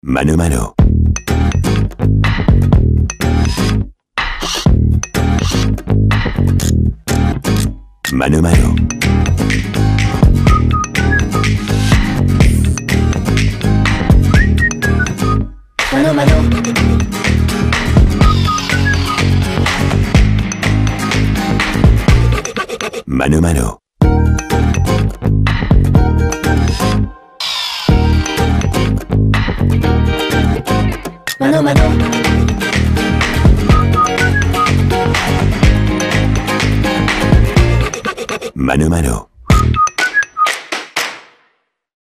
Un timbre de voix allant du médium au grave, Une voix élégante et chaleureuse, avec une large palette de styles d'interprétation et de tons.
Voix off masculine de la nouvelle identité sonore de Mano Mano
Voix off pour les signatures de vos publicités ou films d’entreprises